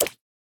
Minecraft Version Minecraft Version 1.21.5 Latest Release | Latest Snapshot 1.21.5 / assets / minecraft / sounds / mob / frog / long_jump3.ogg Compare With Compare With Latest Release | Latest Snapshot
long_jump3.ogg